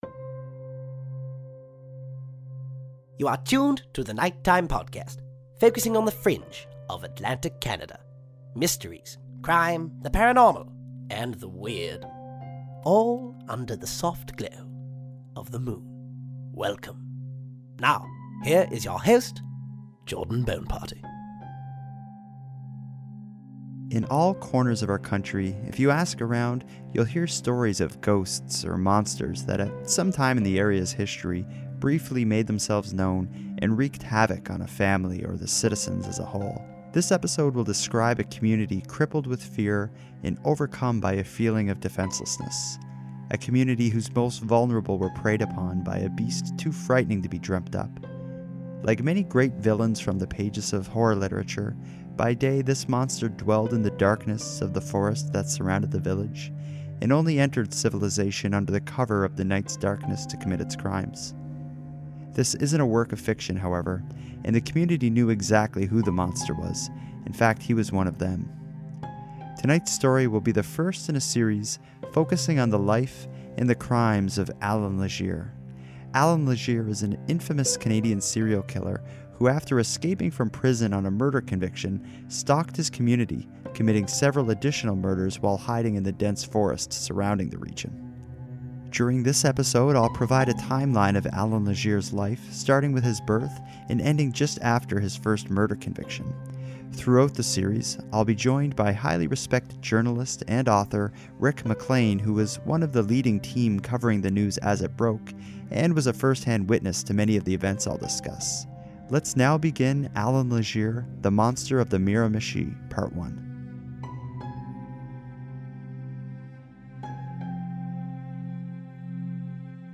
excerpts of an interview